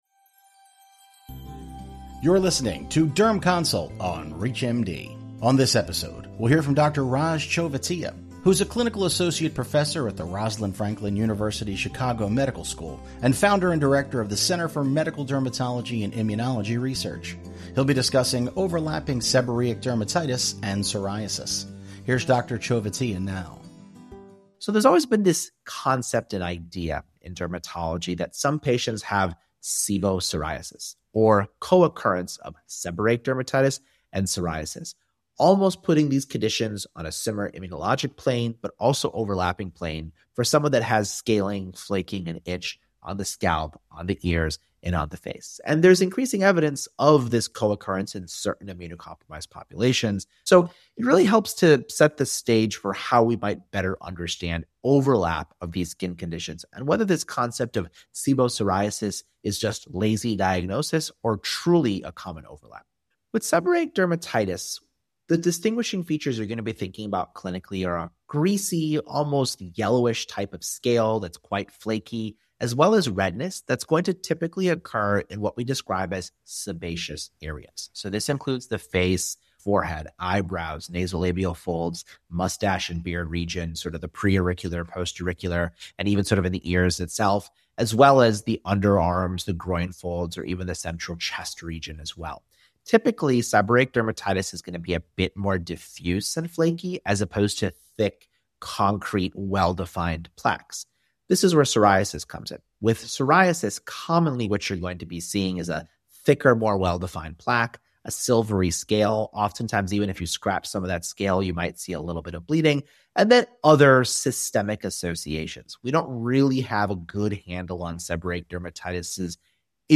PhD Sebopsoriasis—where features of seborrheic dermatitis and psoriasis converge—remains a diagnostic gray area in dermatology. In this expert-led discussion